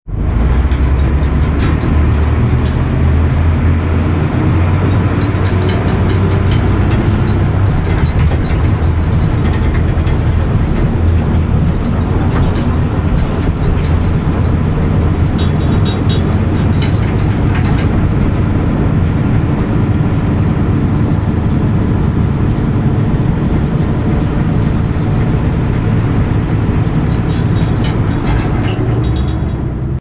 Interior de un autobús antiguo (motor)
Sonidos: Transportes